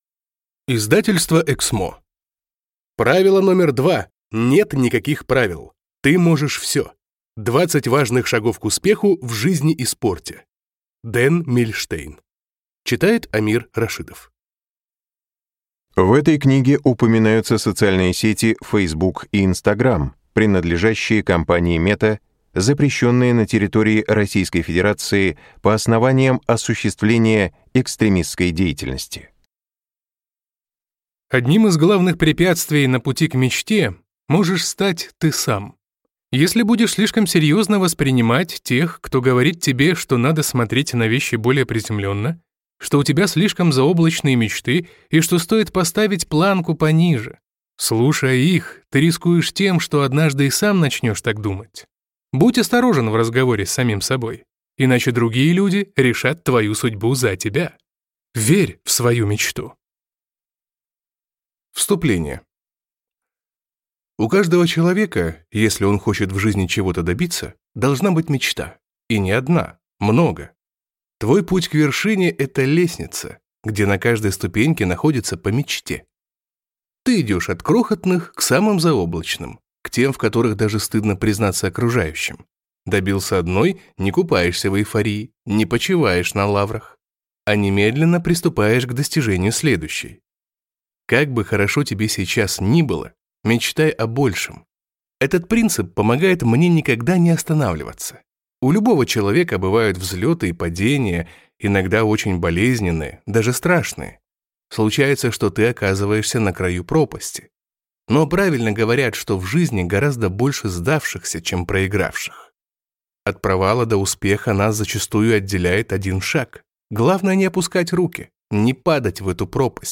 Аудиокнига Правило №2 – нет никаких правил. Ты можешь всё. 20 важных шагов к успеху в жизни и спорте | Библиотека аудиокниг